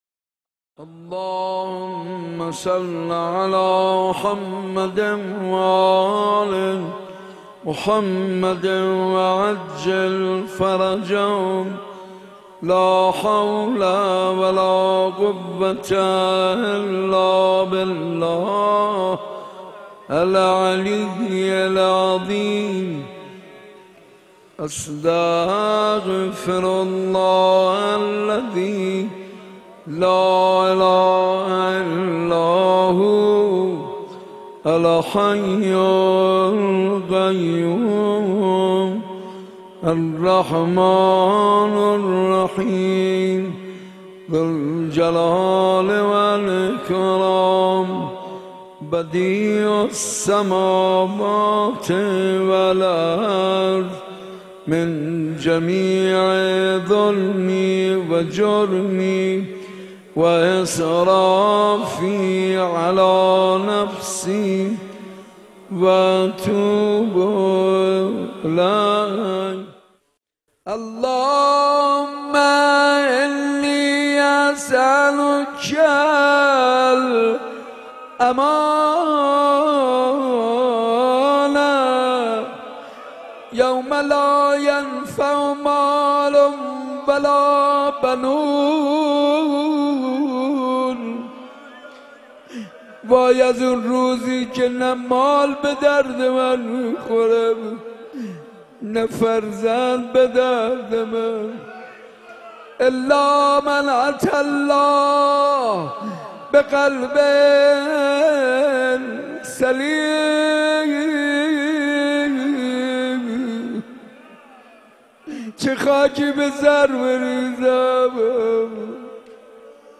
گلچین مراسم شب بیست و دوم